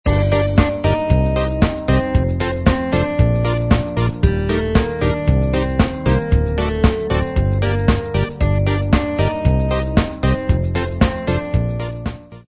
• Пример мелодии содержит искажения (писк).